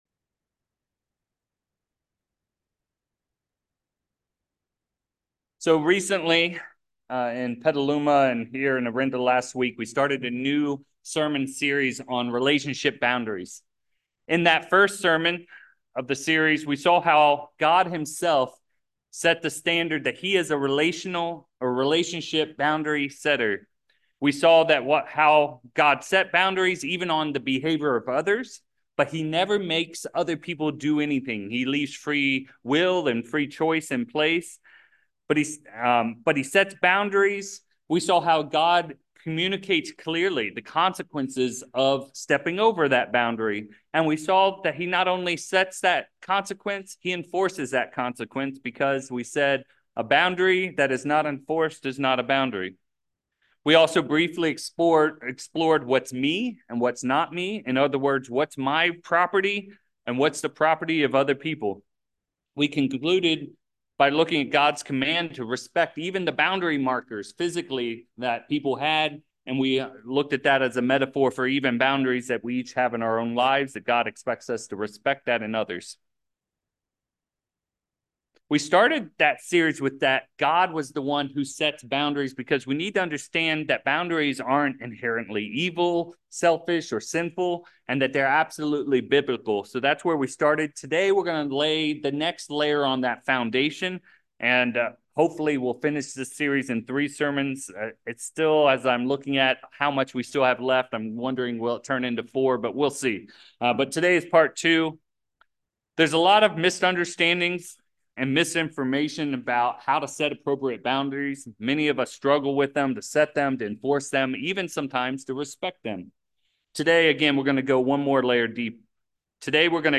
After that, we look at two principles in the Bible: Sowing and reaping and taking action. As we conclude the sermon, we dive into how God created us to have boundaries and how there is a difference between hurt and harm.